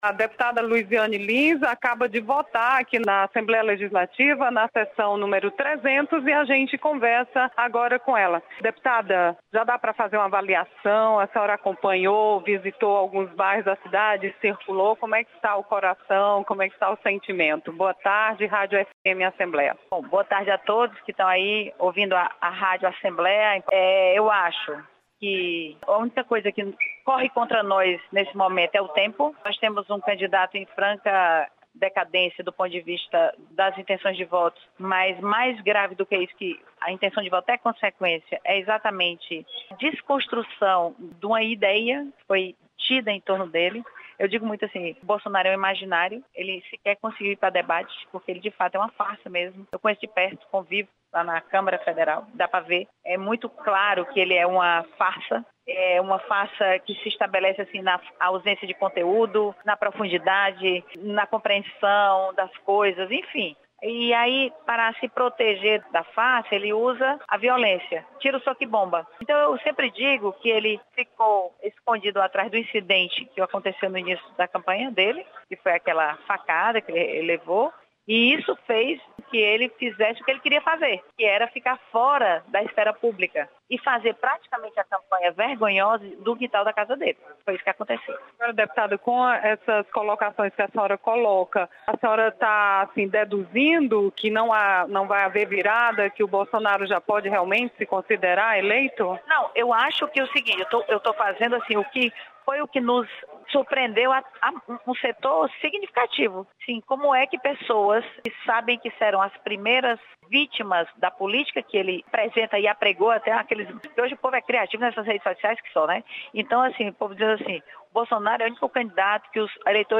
Deputada Luizianne Lins faz contraponto às críticas recebidas pela candidatura petista.